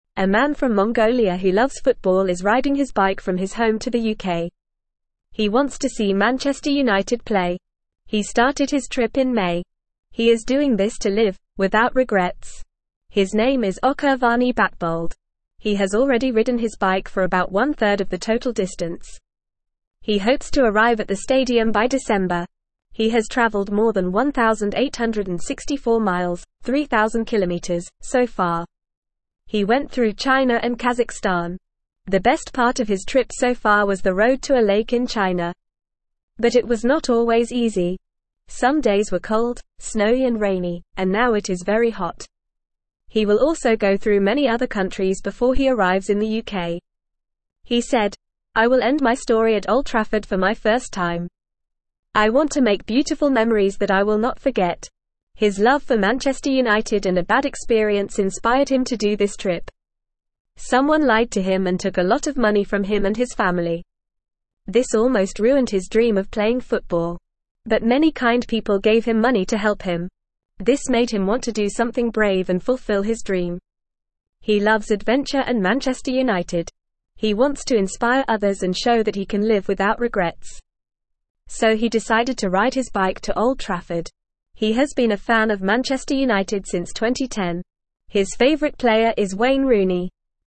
Fast
English-Newsroom-Beginner-FAST-Reading-Man-Rides-Bike-to-UK-for-Football-Game.mp3